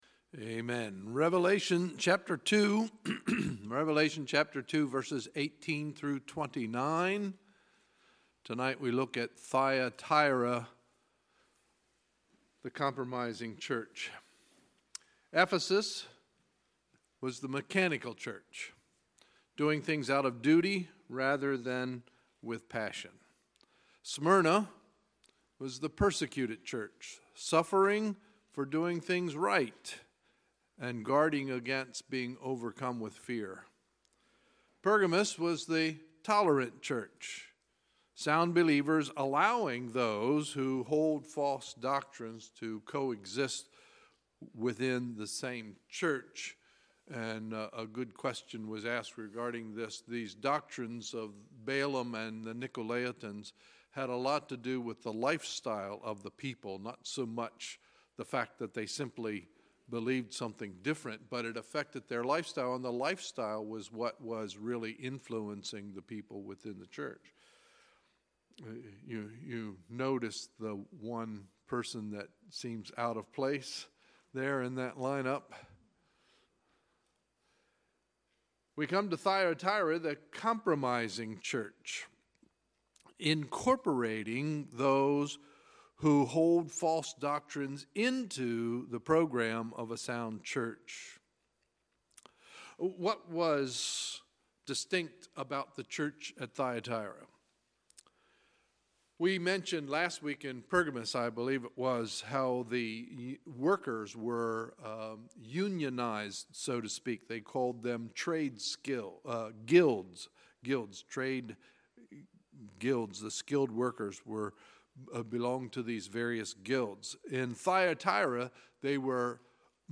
Sunday, March 11, 2018 – Sunday Evening Service